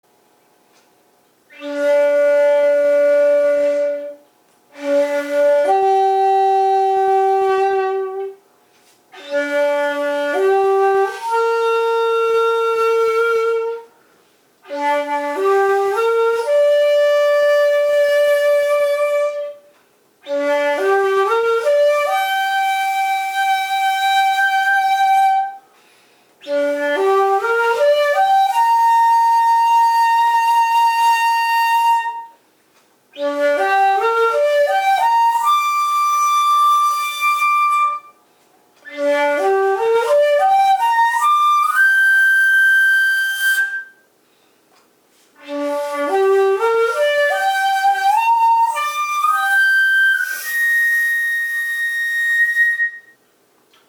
B♭は２，３孔閉じの運指で楽に出ます。
考えあぐねている内に「尺八はカザシ(翳し）」の技法があることに気付き、それならB♭の運指から第１孔をかざしてAを作り次の大甲レにつなげればいいとわかりました。